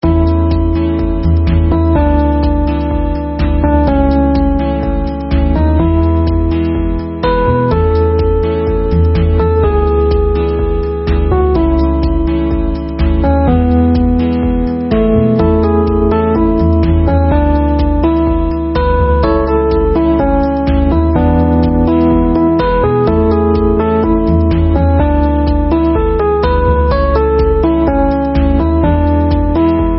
主背景音乐（淡音，慢节奏）.mp3